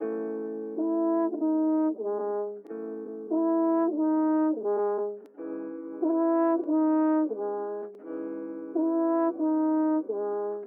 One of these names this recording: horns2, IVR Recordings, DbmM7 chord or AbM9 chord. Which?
horns2